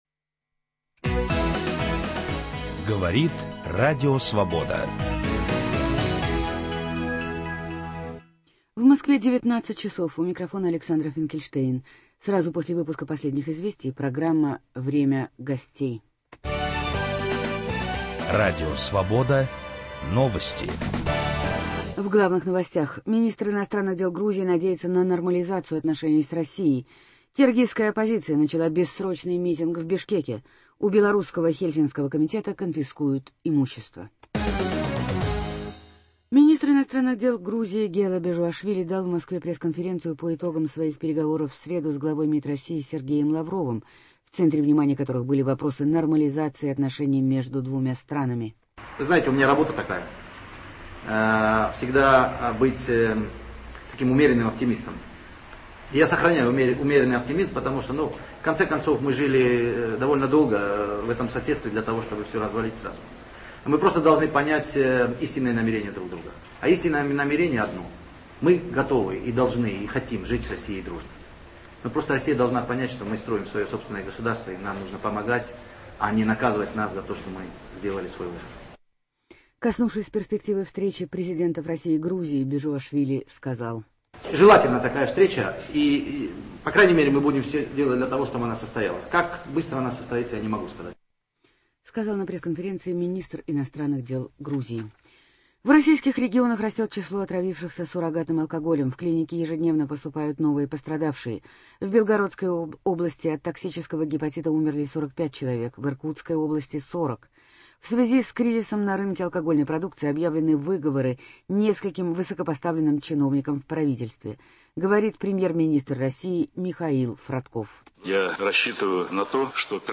Украина, Россия и ВТО. Гость киевской студии - депутат Верховной Рады Украины Наталья Прокопович.